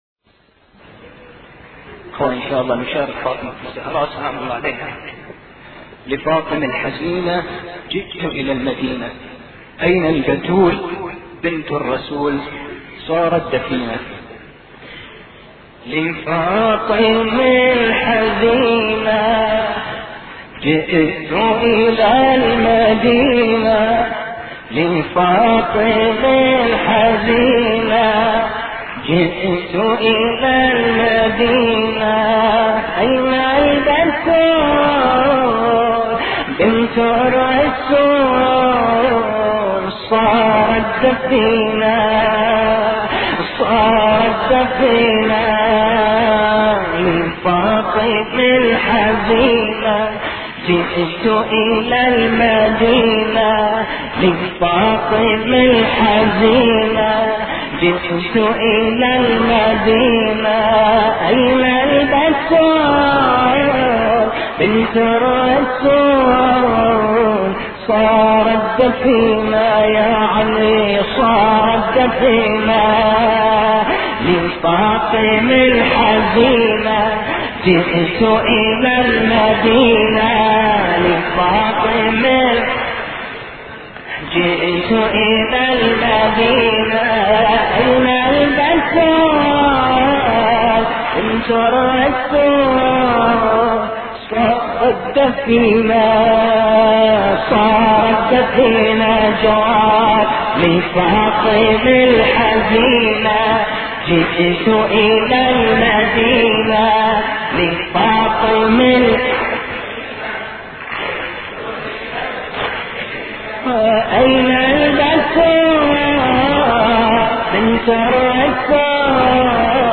مراثي فاطمة الزهراء (س)